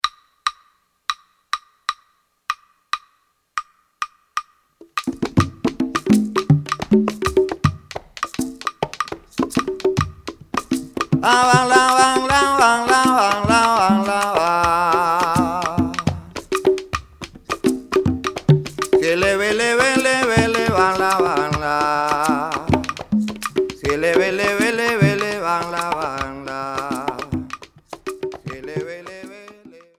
Темп: 100 bpm